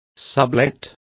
Complete with pronunciation of the translation of sublet.